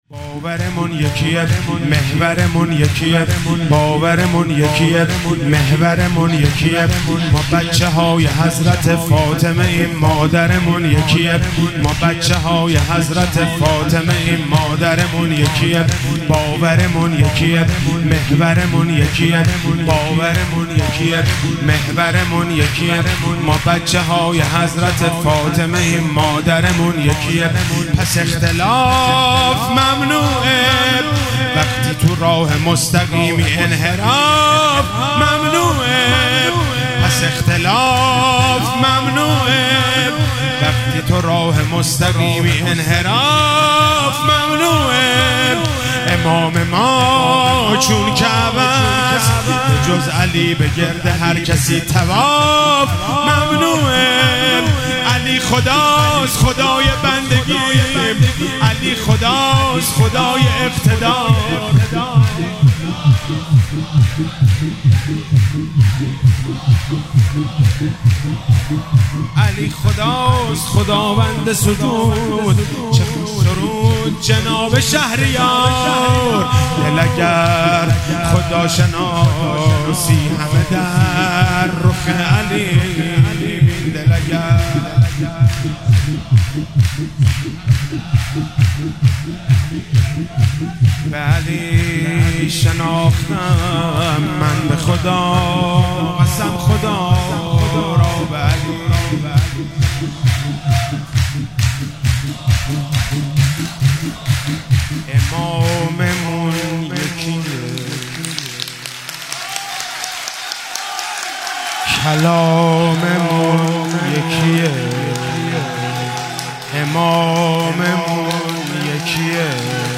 شور- باورمون یکیه محورمون یکیه
مراسم جشن شب سوم ویژه برنامه عید سعید غدیر خم 1444